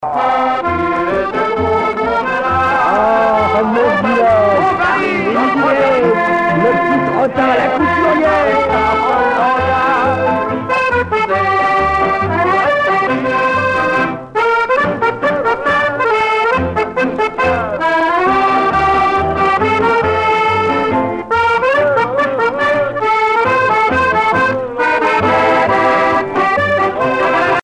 danse : one-step
Pièce musicale éditée